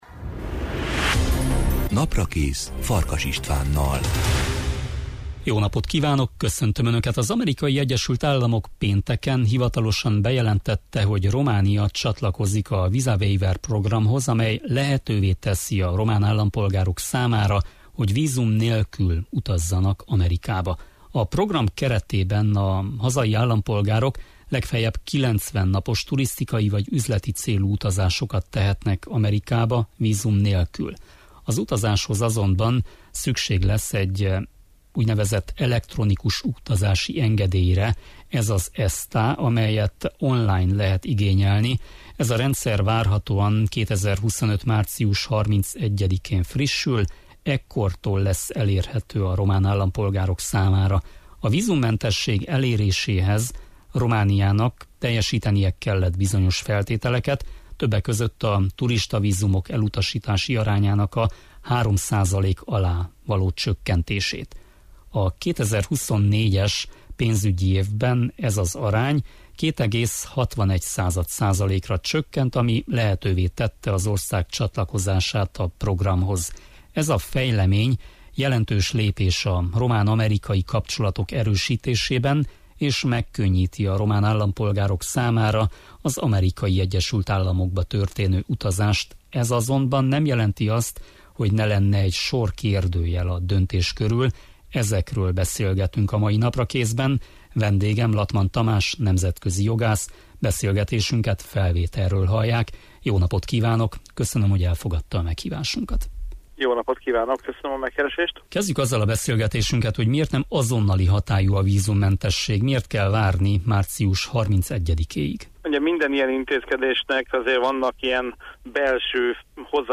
Ezekről beszélgettünk a Naprakészben.